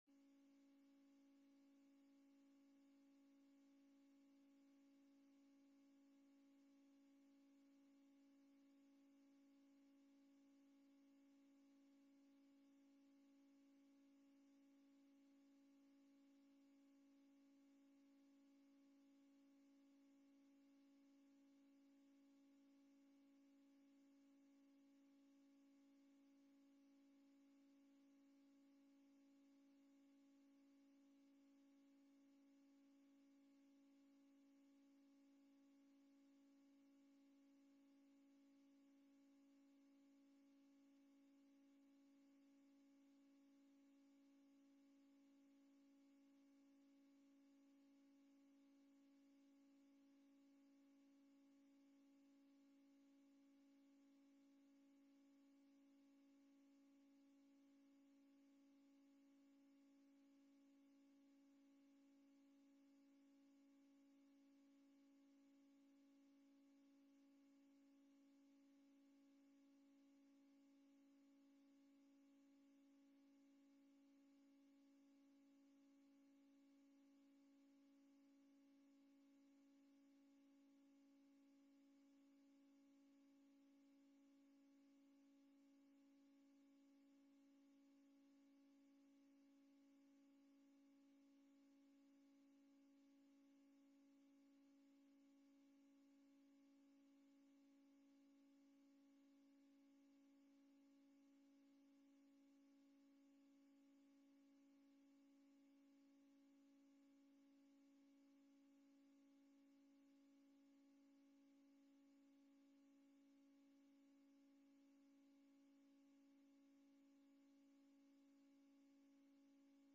De vergadering wordt digitaal gehouden gezien de aangescherpte maatregelen.